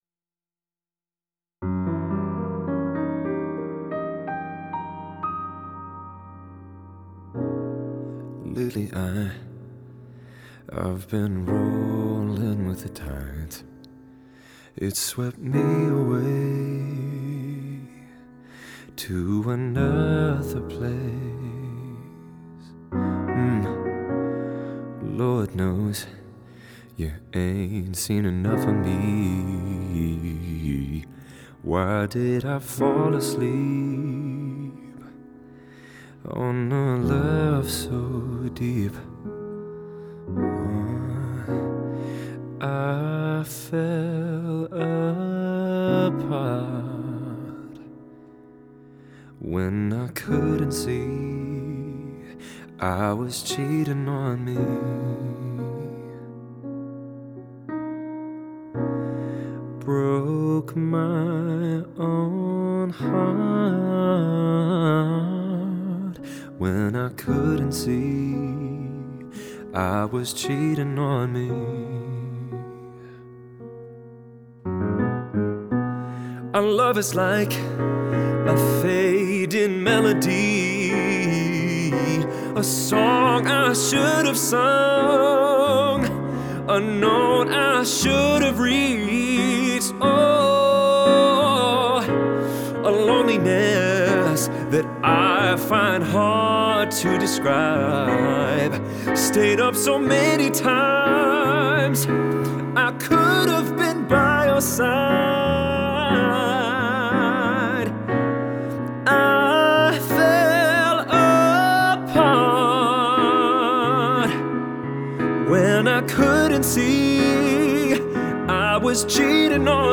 Perhaps the piano is a bit too "modern" sounding?